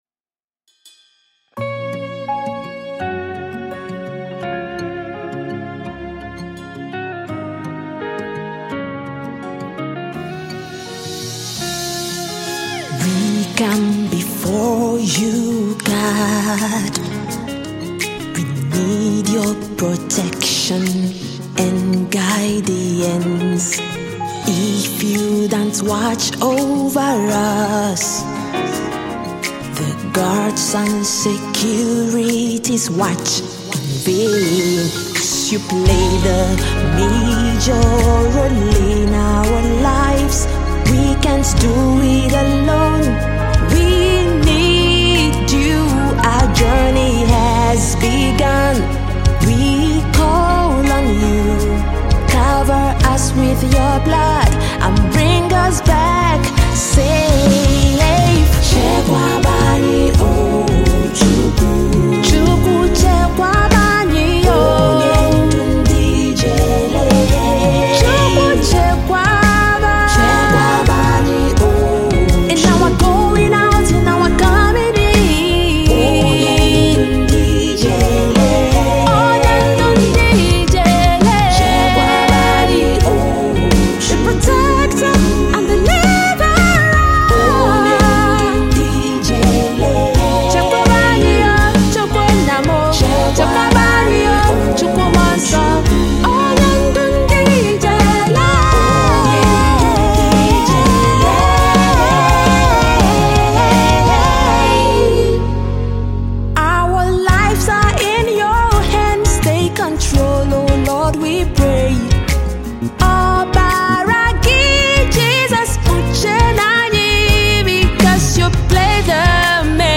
Nigerian gospel music minister and songwriter
guitars